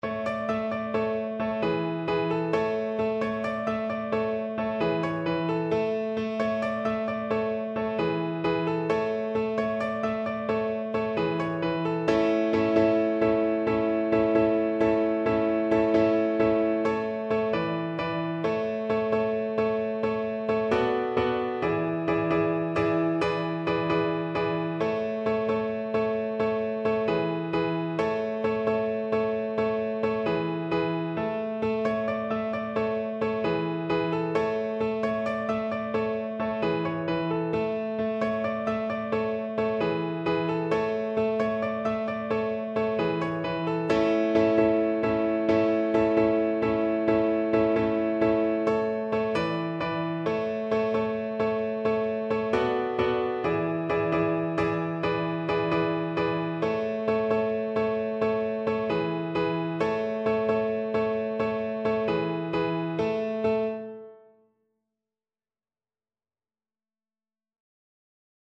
Violin
Traditional Music of unknown author.
7/8 (View more 7/8 Music)
A major (Sounding Pitch) (View more A major Music for Violin )
Allegro =132 (View more music marked Allegro)